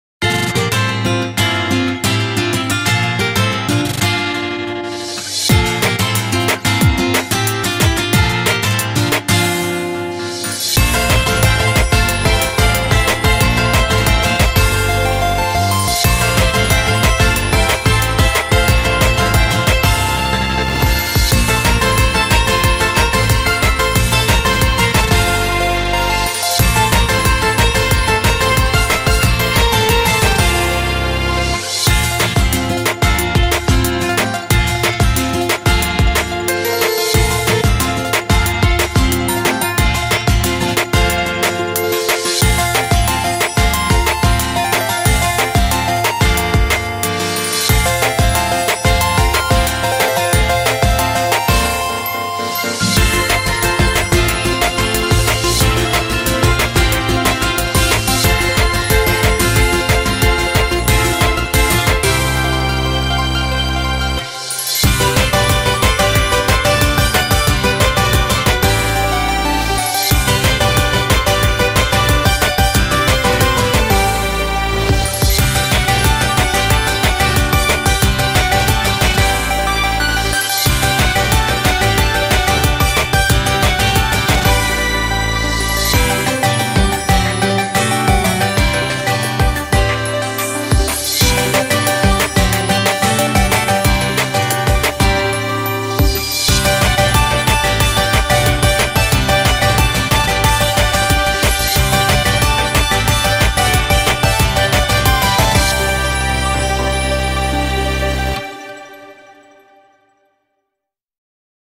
BPM182
Audio QualityPerfect (Low Quality)